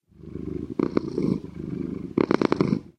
purr2.ogg